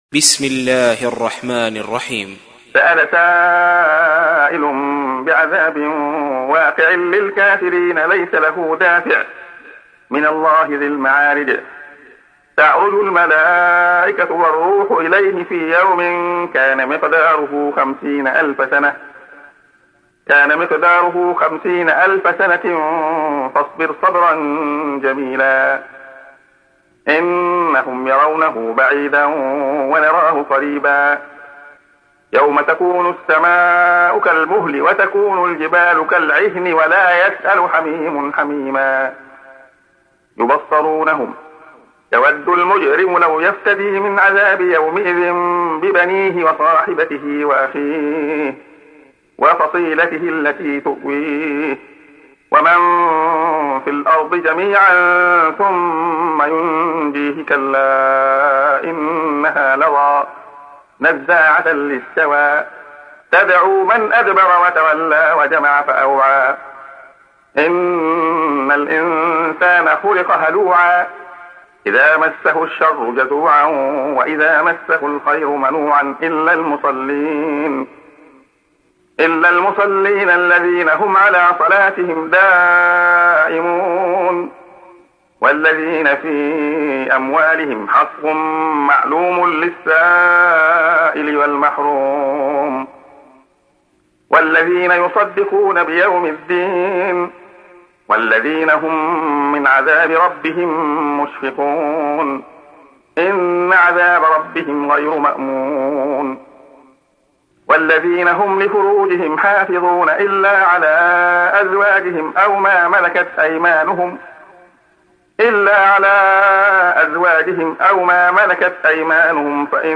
تحميل : 70. سورة المعارج / القارئ عبد الله خياط / القرآن الكريم / موقع يا حسين